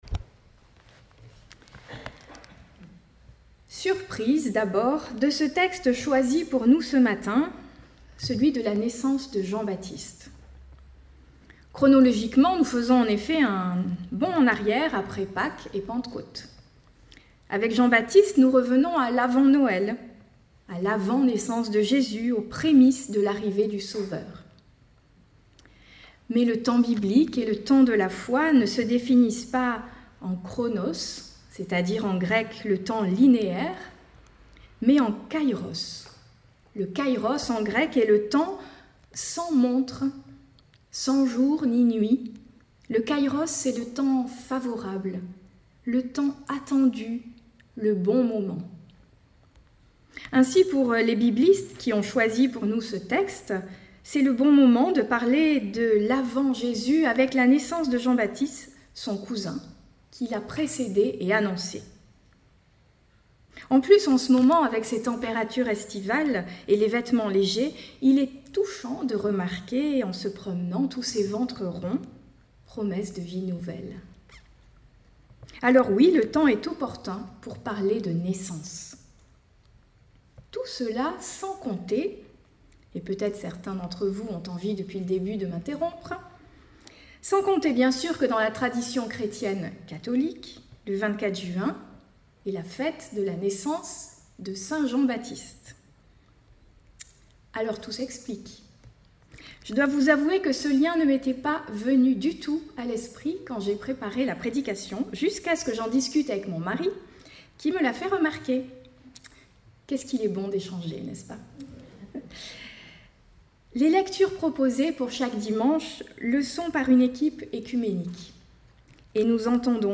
Podcasts prédications